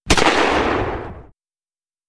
shot.wav